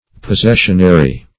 Search Result for " possessionary" : The Collaborative International Dictionary of English v.0.48: Possessionary \Pos*ses"sion*a*ry\, a. Of or pertaining to possession; arising from possession.